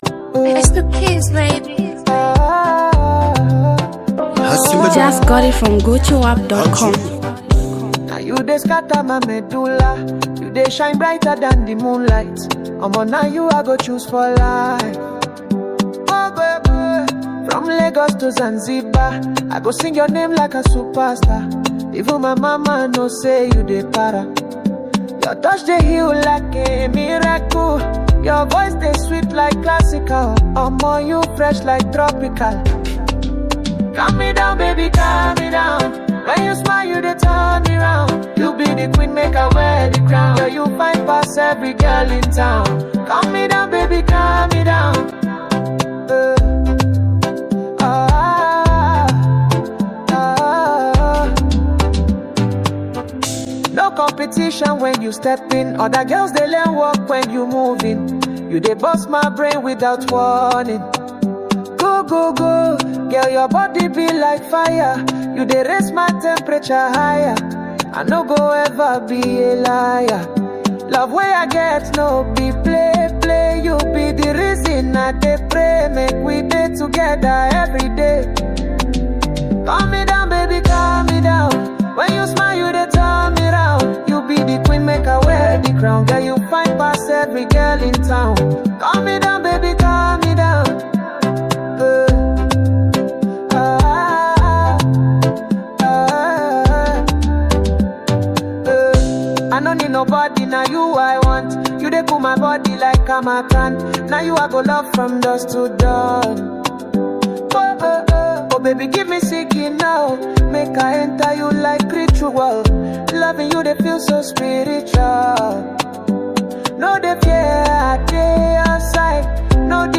Get ready to groove to the sultry rhythms